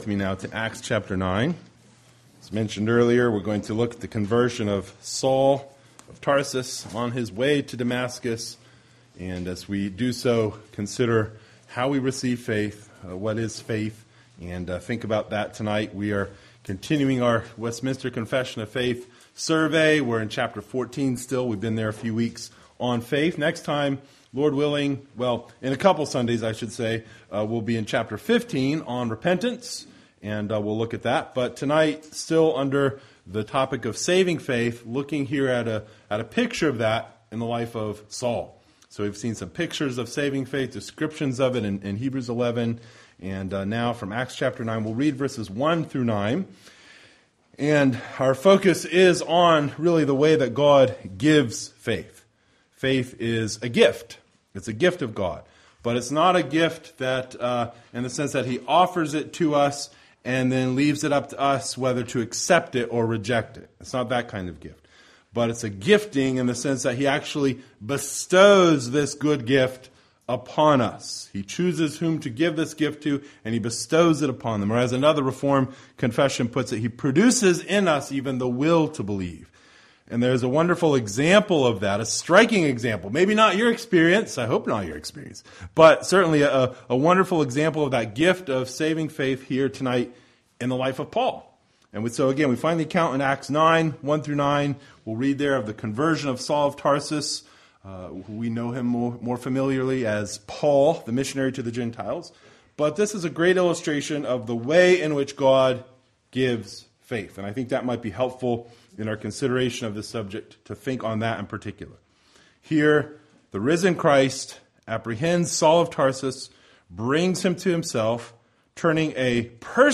The Westminster Confession of Faith Passage: Acts 9:1-9 Service Type: Sunday Evening Related « Faith of Abel